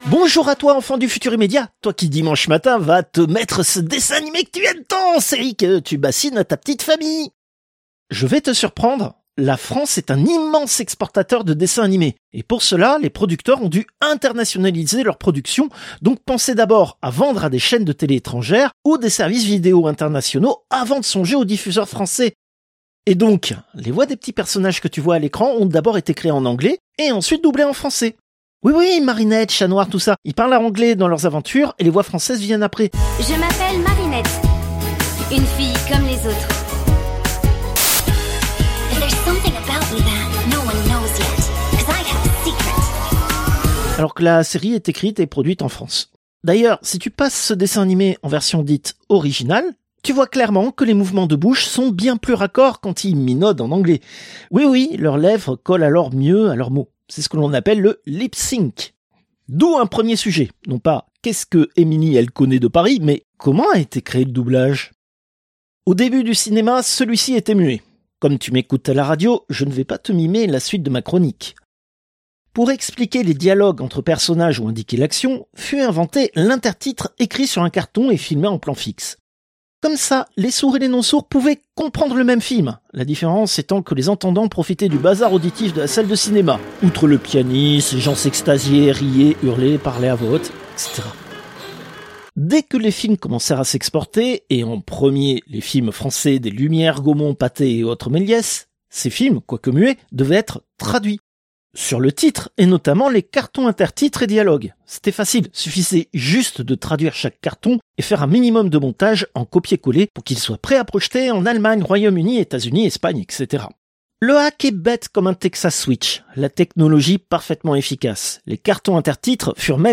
Extrait de l'émission CPU release Ex0235 : Doublage robot, première partie.